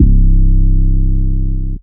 DDK1 808 4.wav